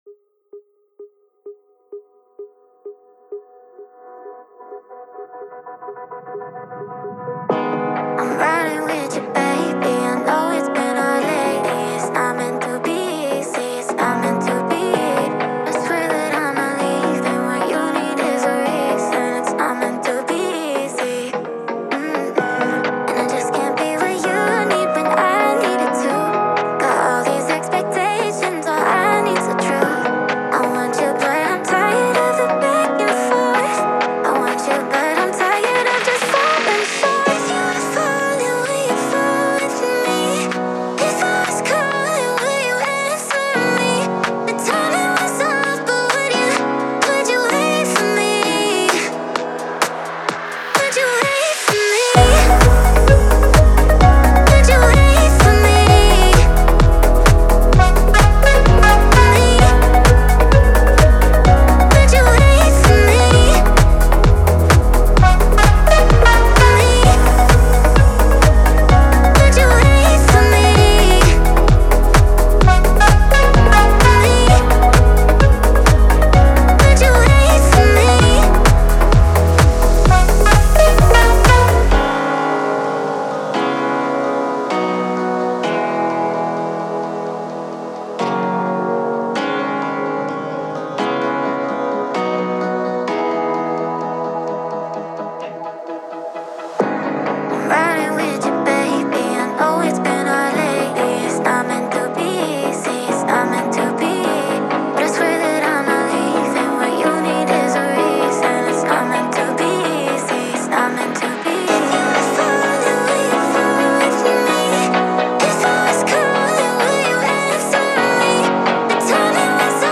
Music / Pop